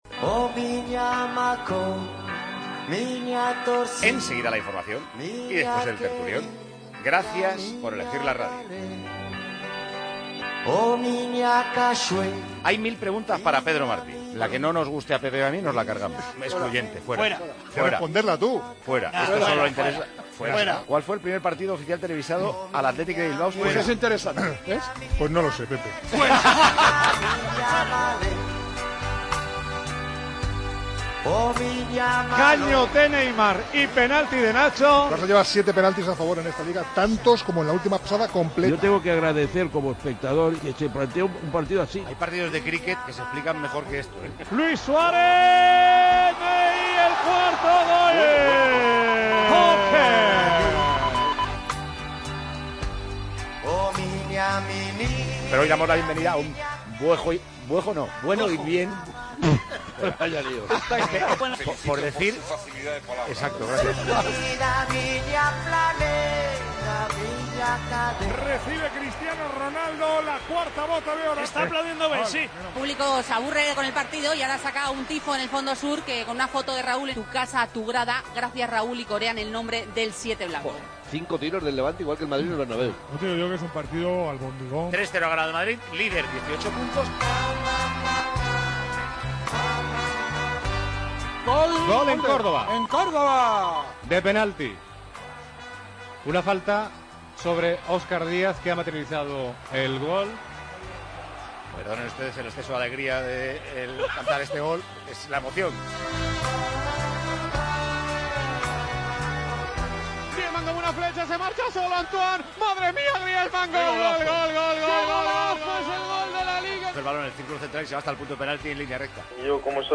El Real Madrid recupera el liderato y tenemos un Tiempo de Juego 'histórico' según Paco González, con Dani Martínez imitando al Rey y a Luis Moya y mucho más.
Con Paco González, Manolo Lama y Juanma Castaño